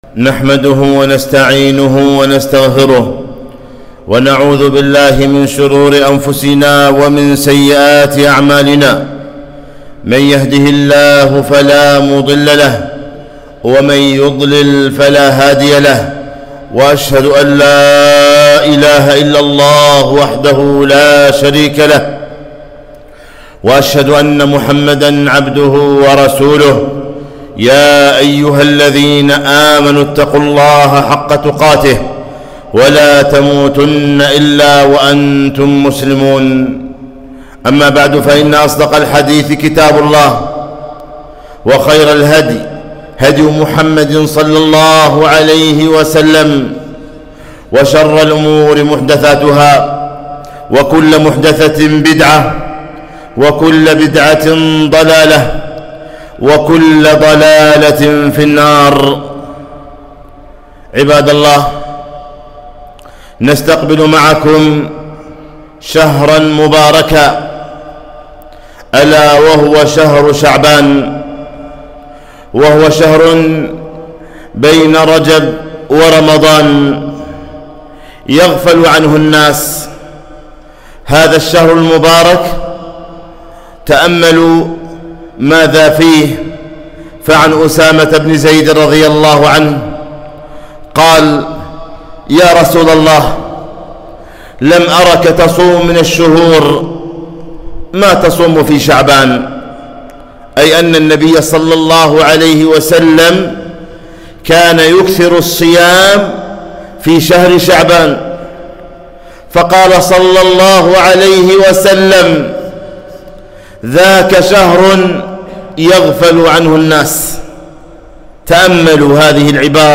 خطبة - فضل شهر شعبان